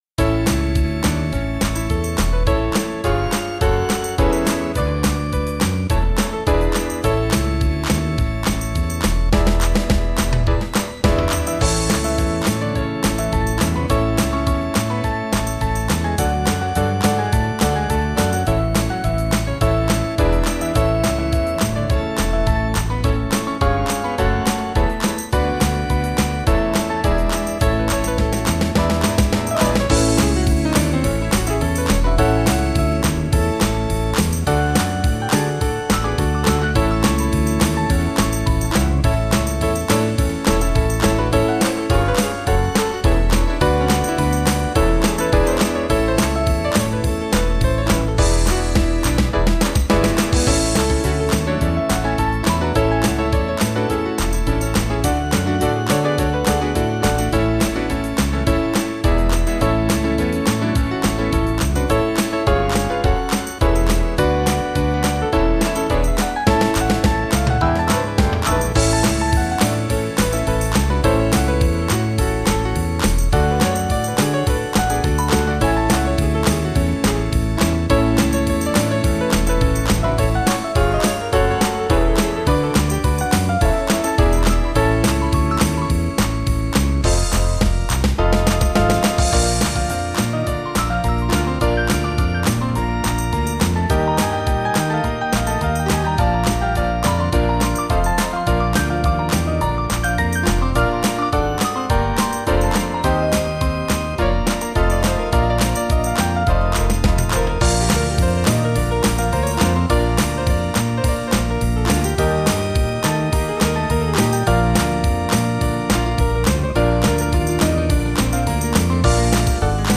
Small Band
Easy Listening   4/A-Bb
UpTempo   285.5kb